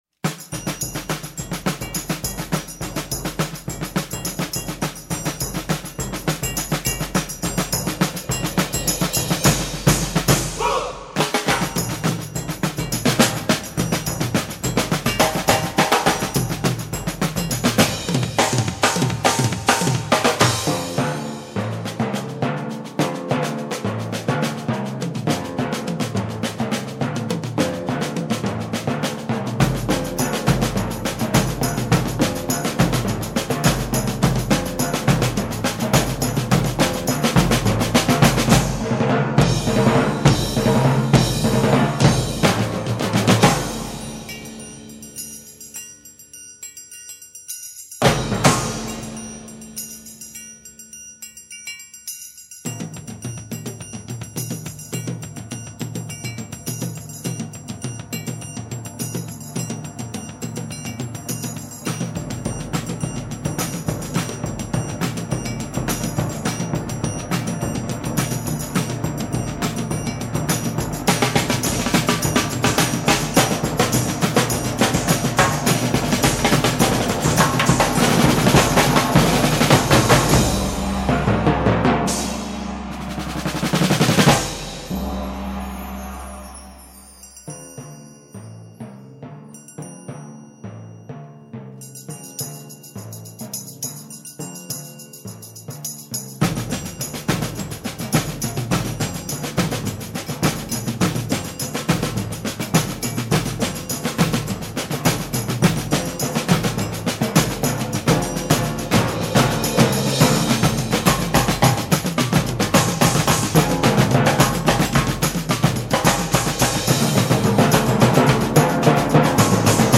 Medium Concert Ensemble